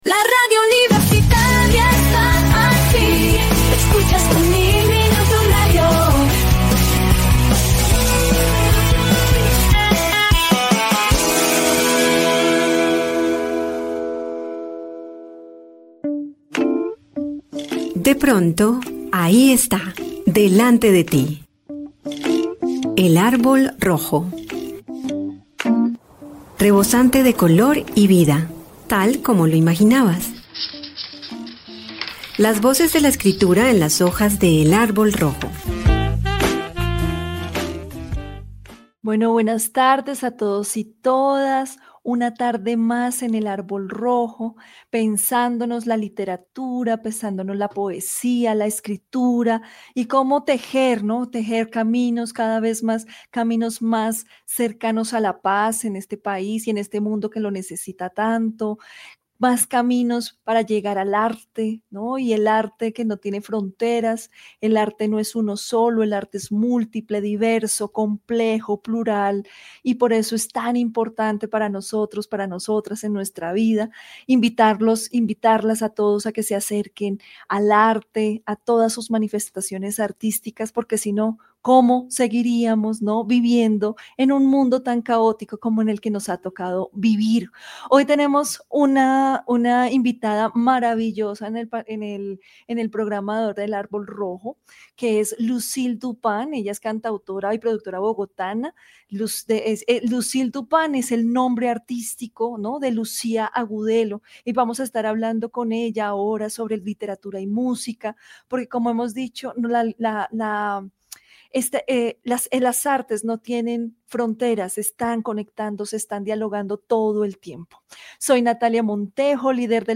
Desde los primeros minutos, la charla fluyó como un encuentro entre amigas que descubren afinidades.